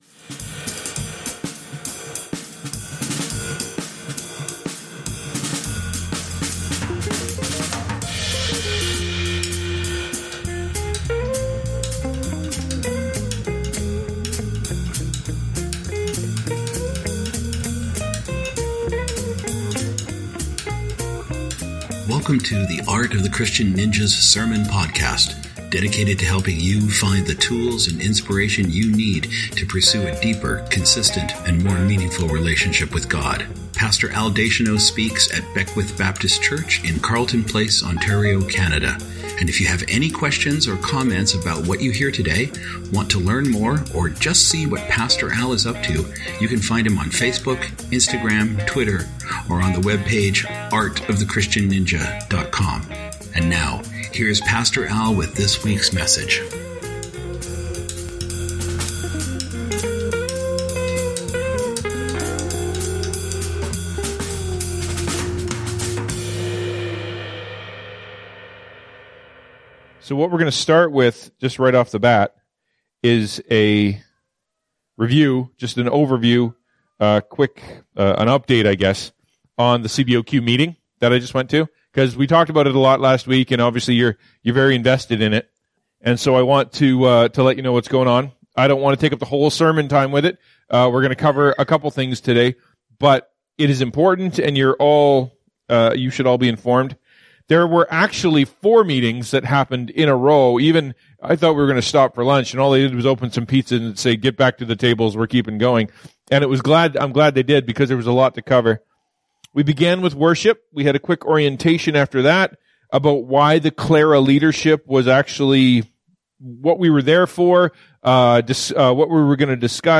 When Should a Christian Leave their Church or Denomination? (Post-CLRA Meeting Report with Q&A)